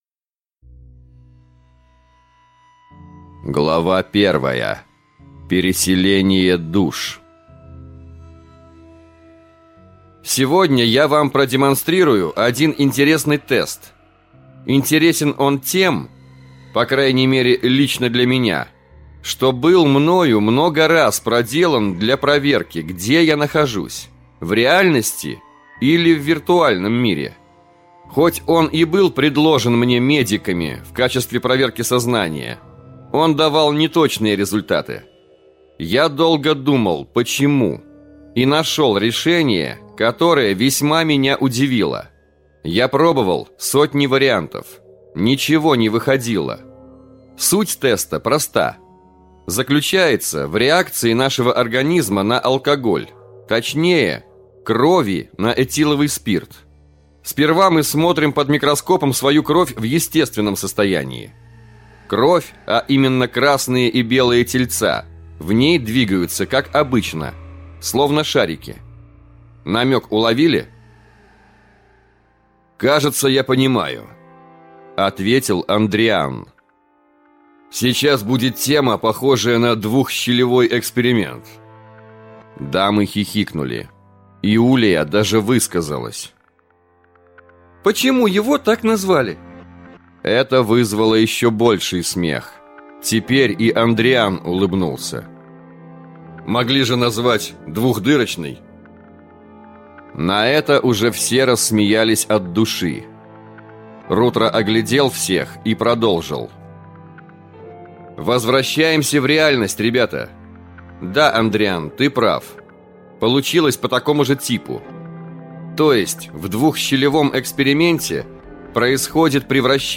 Аудиокнига Параллельные миры – two. Рождение бога | Библиотека аудиокниг